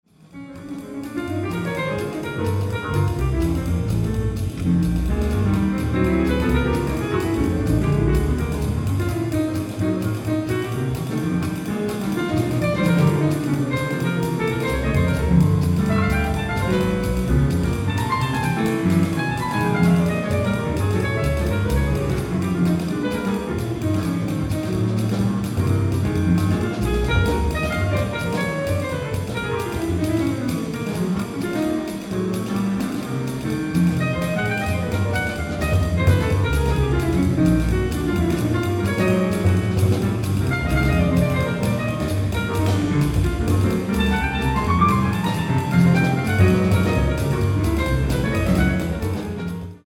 ライブ・アット・サンフランシスコ、カリフォルニア 02/28/1989